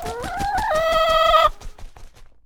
chickens and more chickens